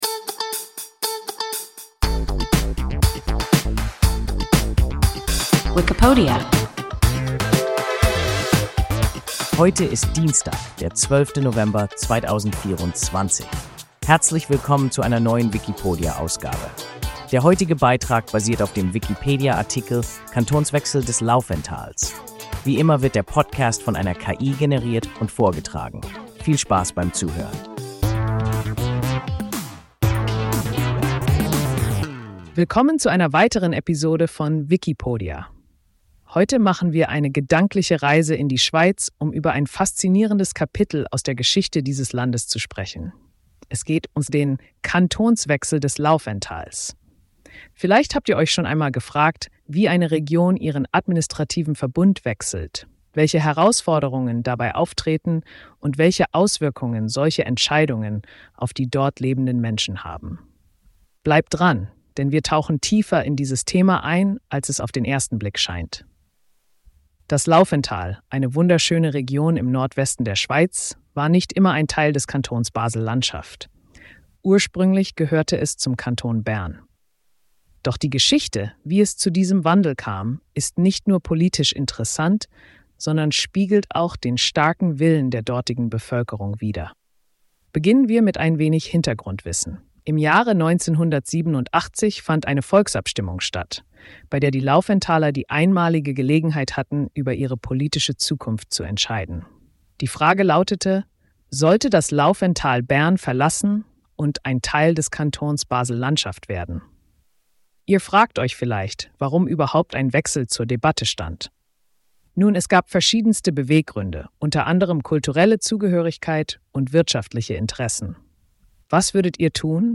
Kantonswechsel des Laufentals – WIKIPODIA – ein KI Podcast